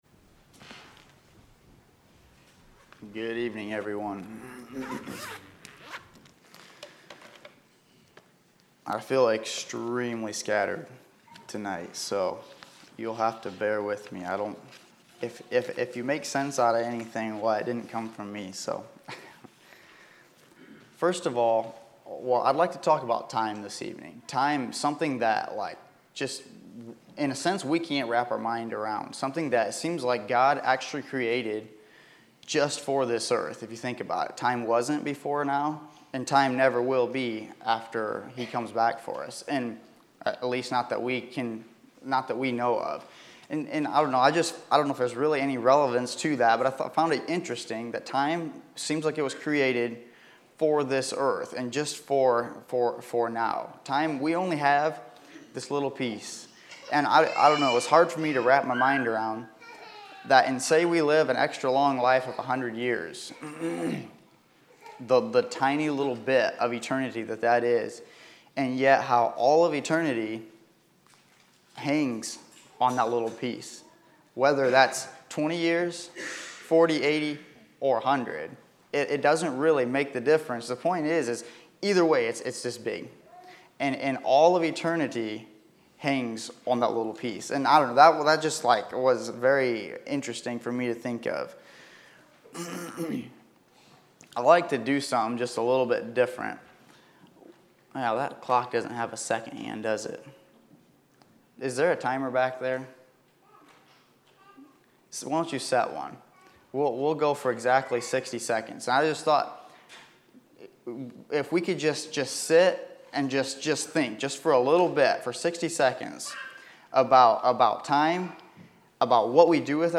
Hymn Singing Messages